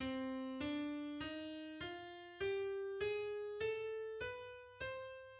Bebop melodic minor scale
The bebop melodic minor scale is derived from the ascending form of the melodic minor scale (jazz minor scale) and has a chromatic passing note between the 5th and 6th scale degrees.